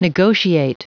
Prononciation du mot negotiate en anglais (fichier audio)